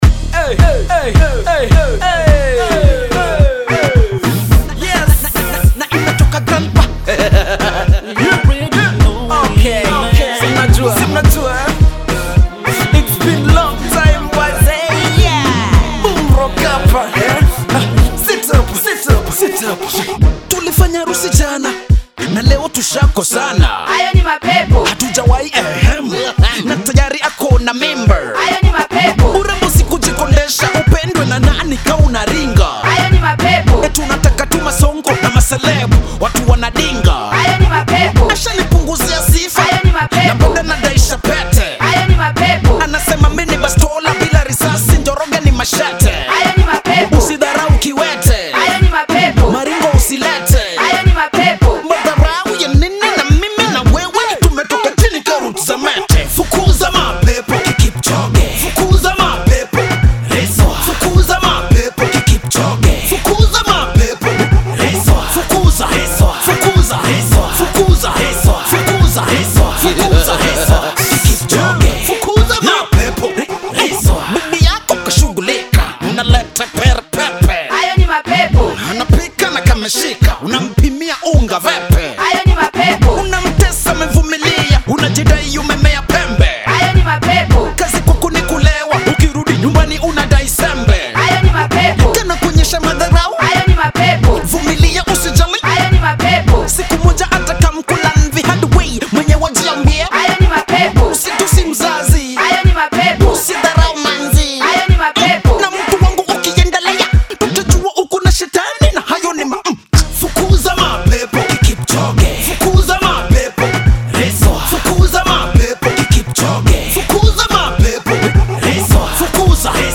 good quality street music
This is a banger!!!!!